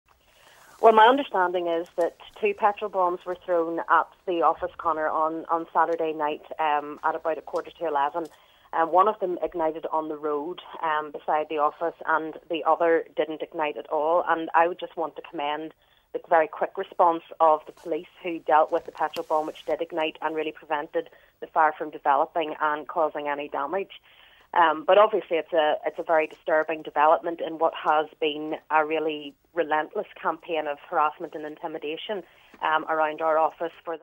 LISTEN: East Belfast MP Naomi Long speaks out about weekend petrol bomb attacks